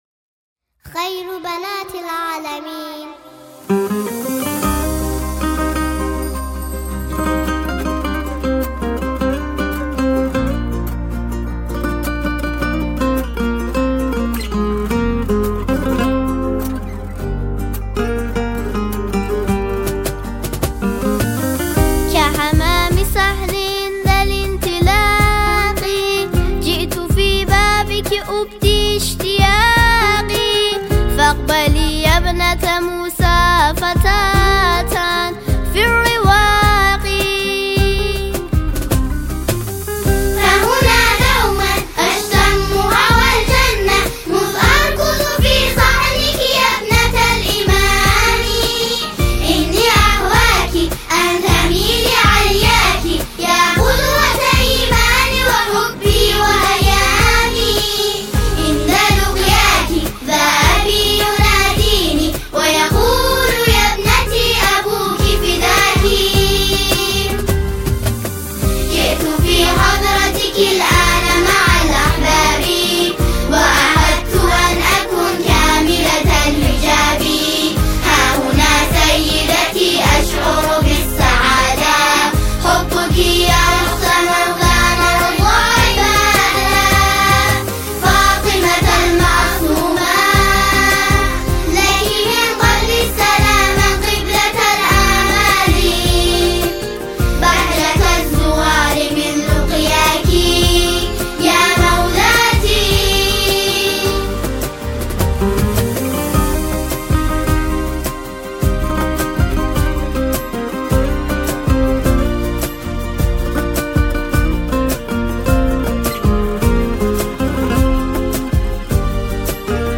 ویژه ولادت حضرت معصومه سلام الله علیها